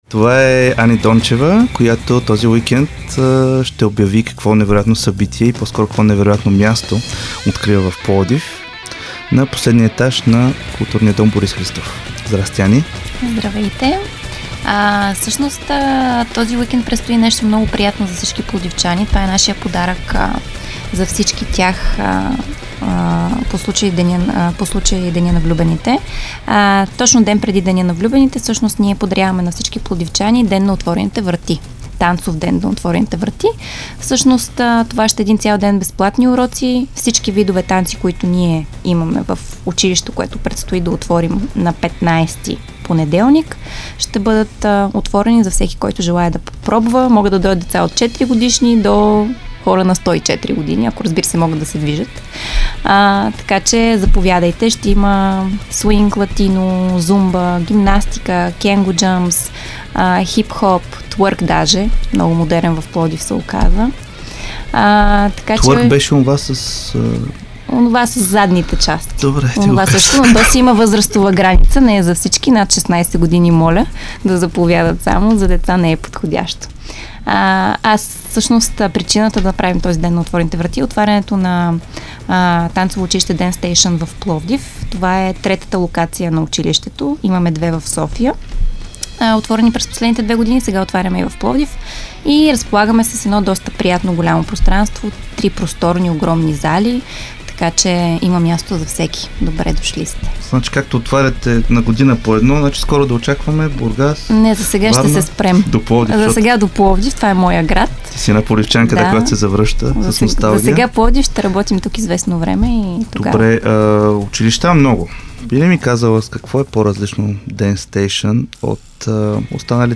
разговора